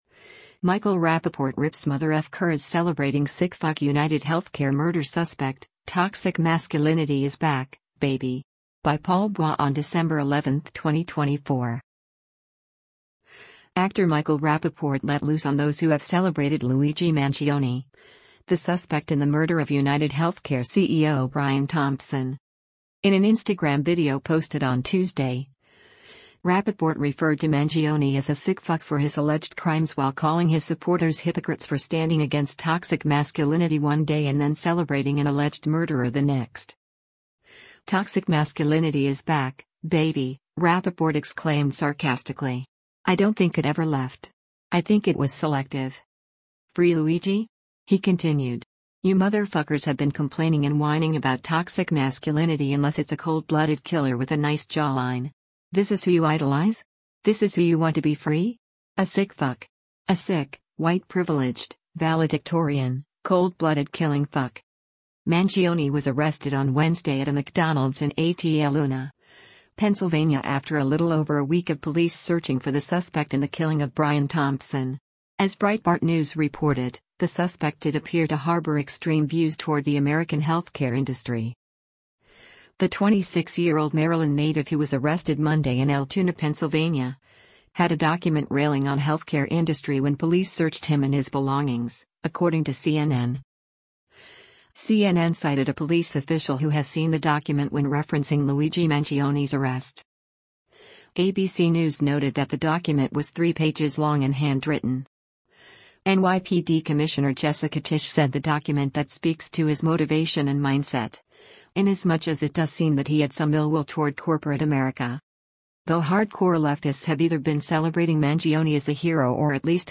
“Toxic masculinity is back, baby!” Rapaport exclaimed sarcastically.